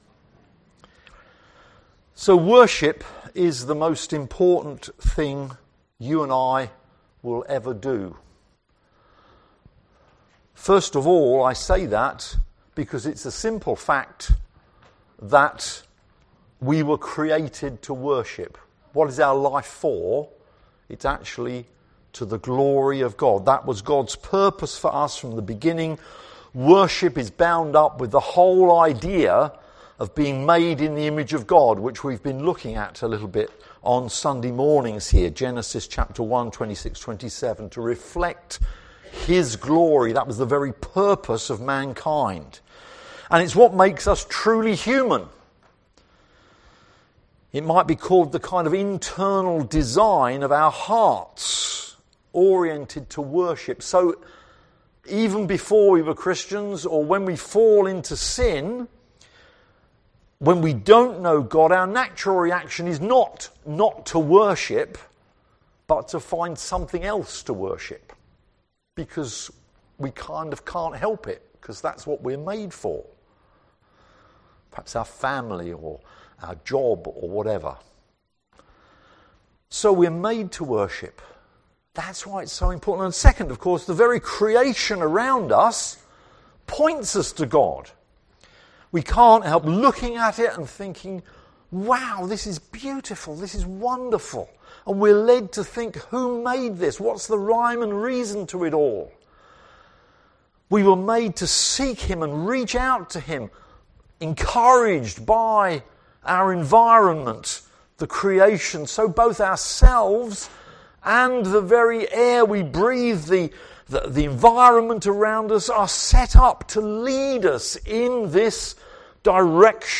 LABC Sermons Hebrews 10v19-25 What happens when we worship?